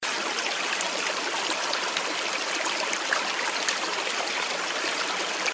StreamLoop.wav